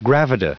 Prononciation du mot gravida en anglais (fichier audio)